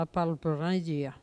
Vendée
Catégorie Locution